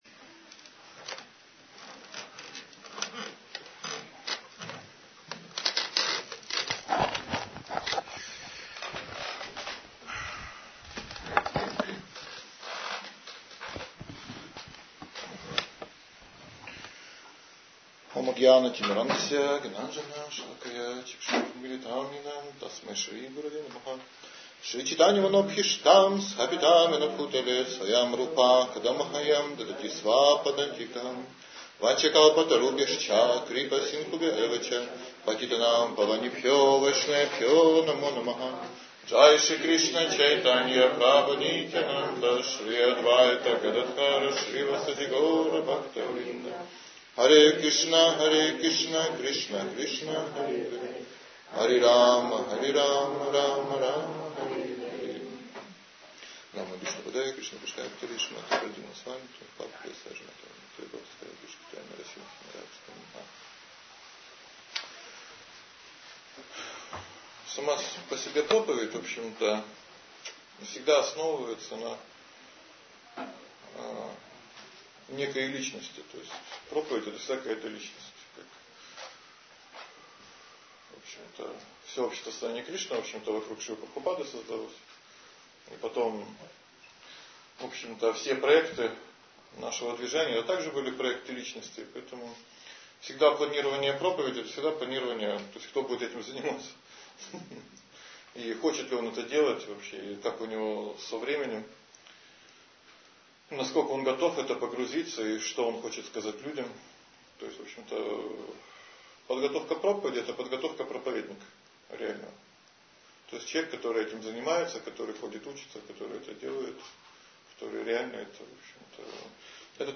Проповеди 8
Проповедь